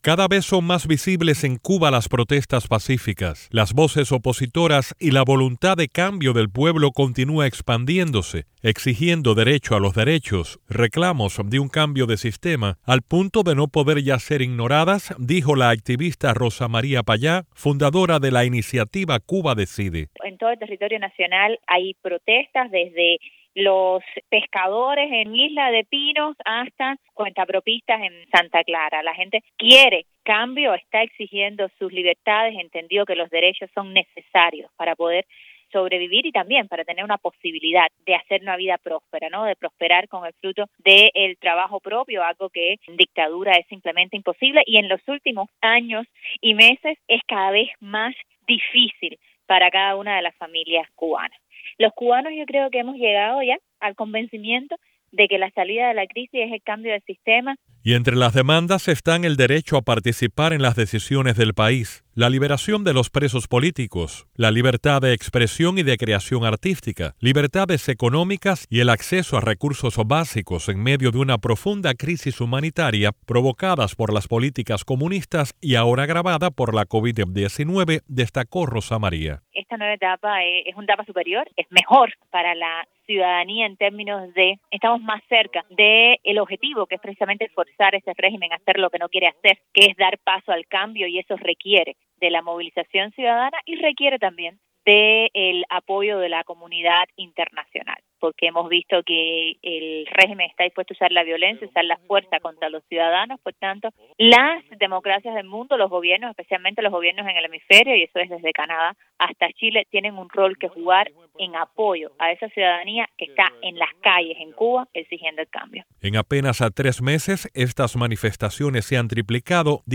Declaraciones de Rosa María Payá a Radio Martí